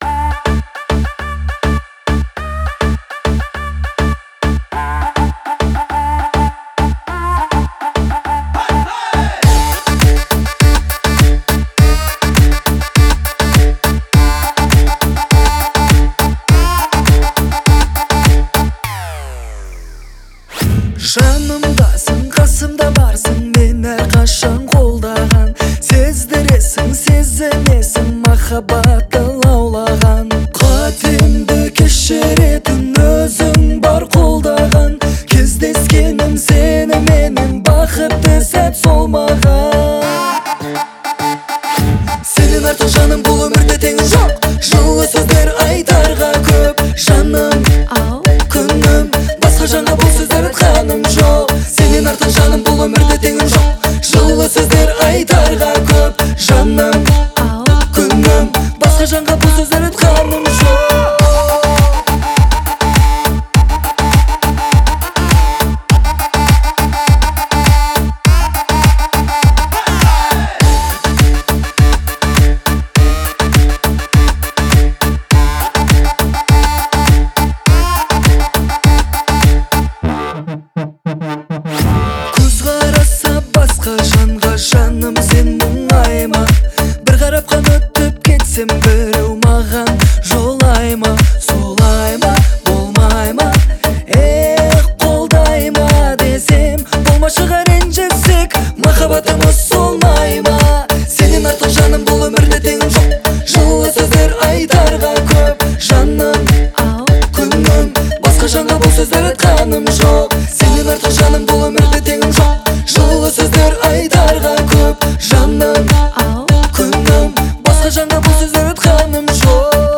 это трогательная казахская песня в жанре поп-фолк